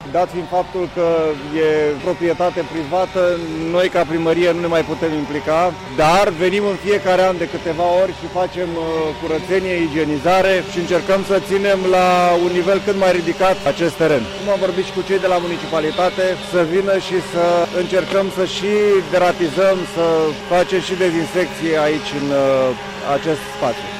Primarul sectorului 3, Robert Negoiță